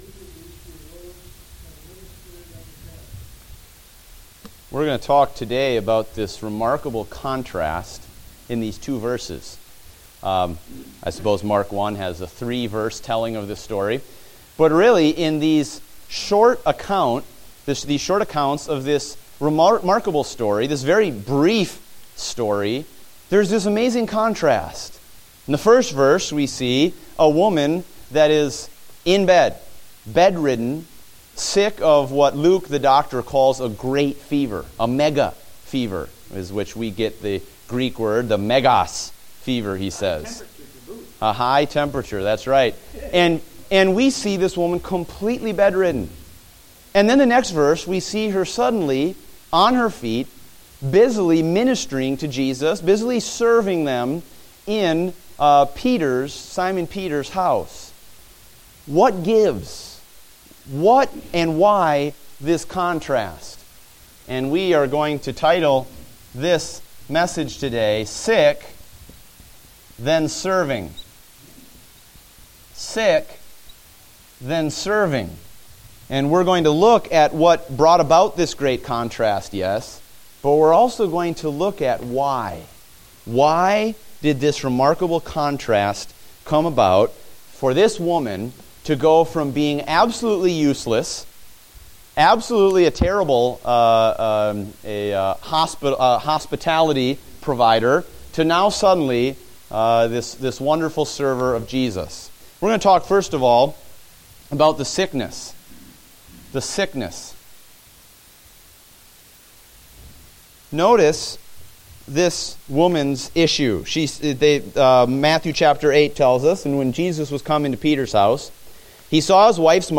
Date: October 25, 2015 (Adult Sunday School)